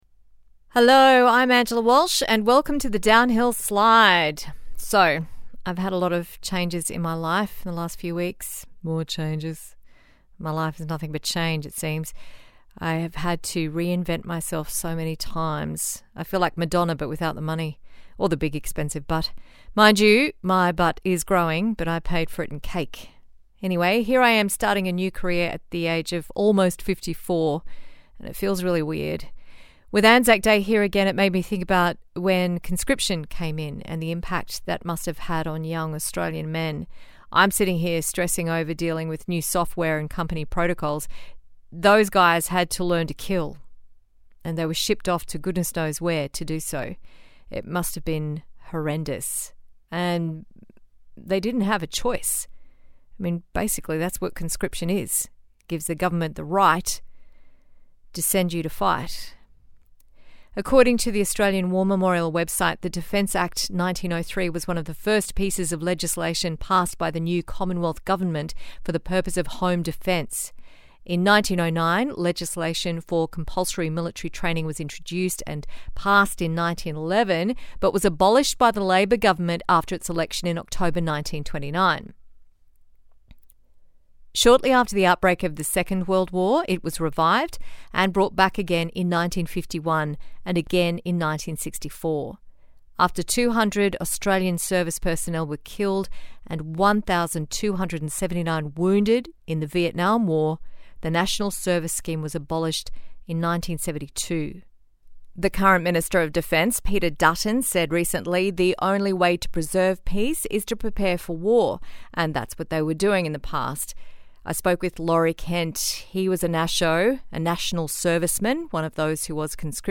A chat about conscription in Australia